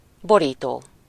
Ääntäminen
IPA: [ku.vɛʁ.tyʁ]